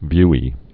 (vyē)